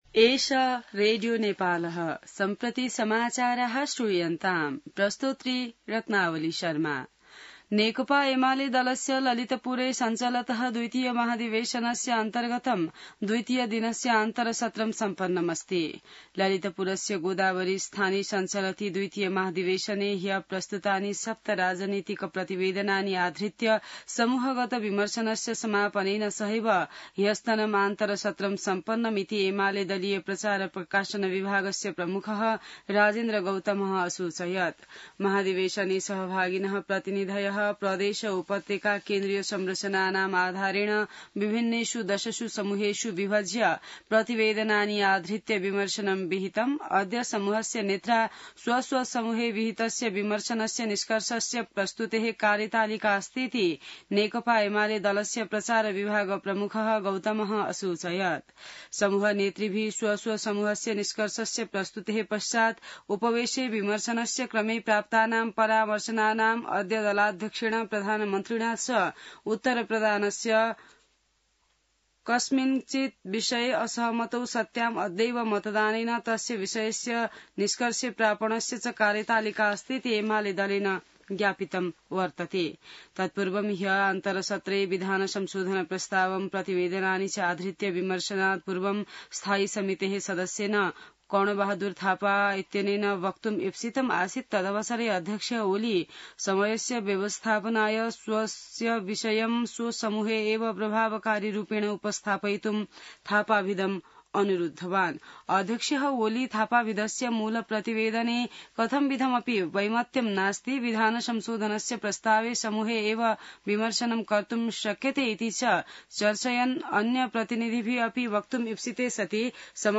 संस्कृत समाचार : २२ भदौ , २०८२